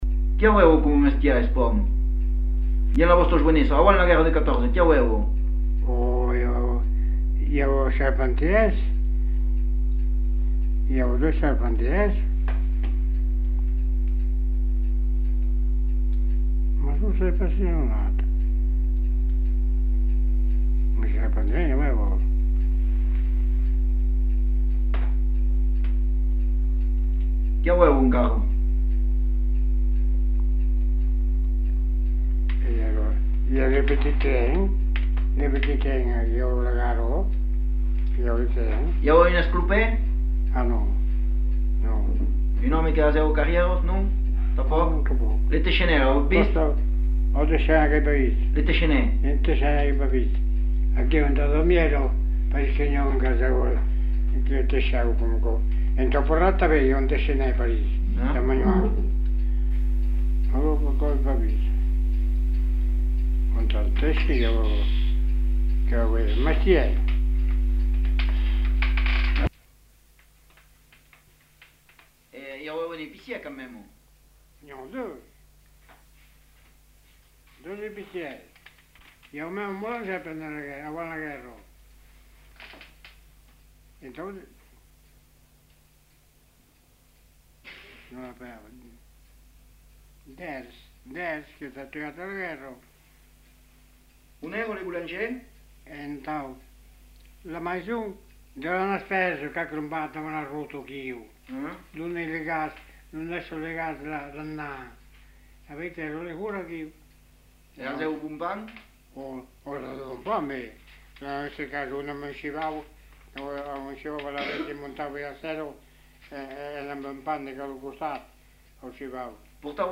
Genre : témoignage thématique